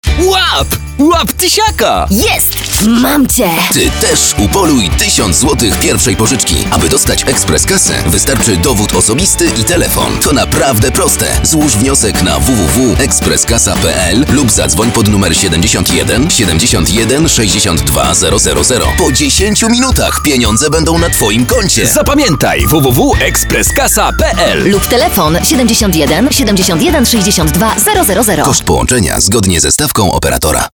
Spoty radiowe usłyszymy między innymi w Jedynce, Trójce, TOK FM, Radiu Rock i lokalnych rozgłośniach.